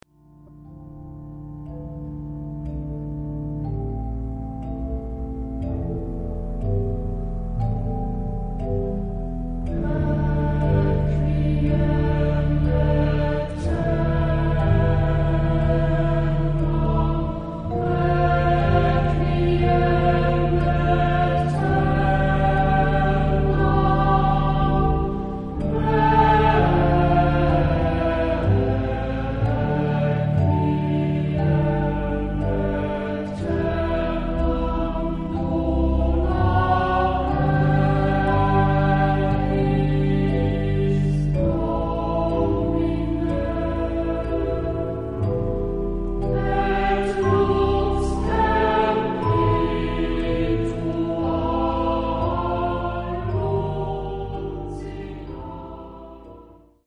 Mood of the piece: sombre ; dramatic ; intense